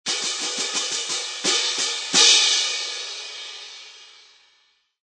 CYMBSOLO.mp3